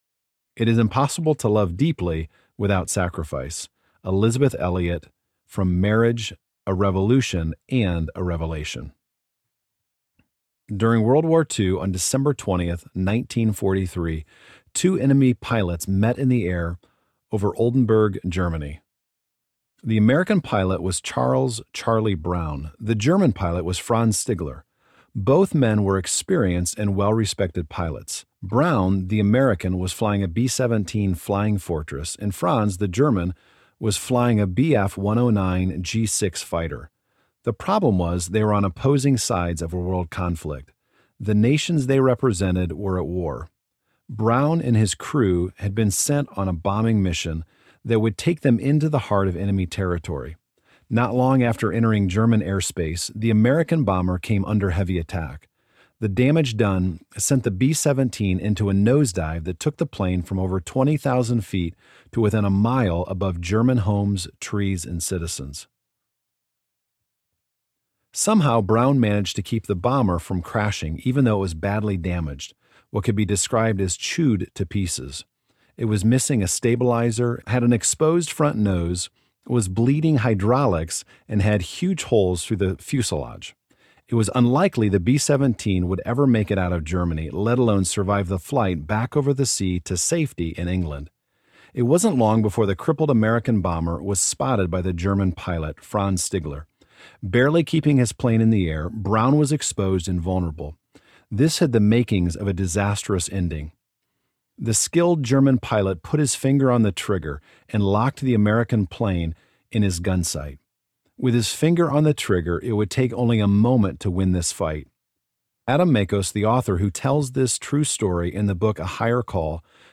For Better or for Kids Audiobook
5.17 Hrs. – Unabridged